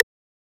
rim3.wav